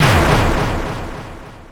snd_heavydamage.wav